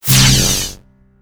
alien_bellow_01.ogg